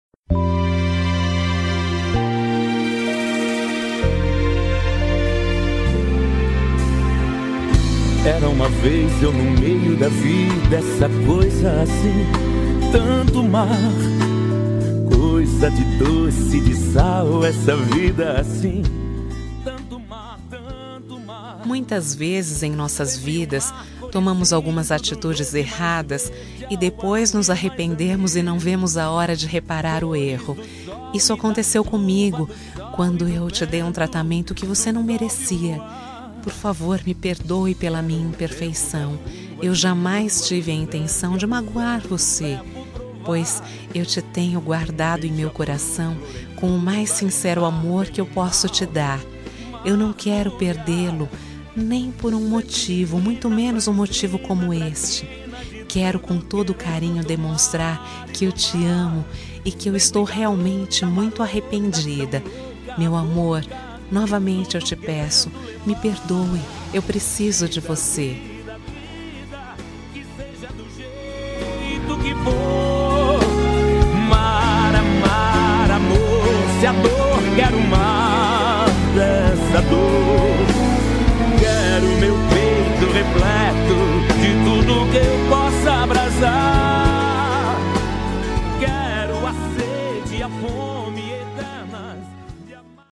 Voz Feminina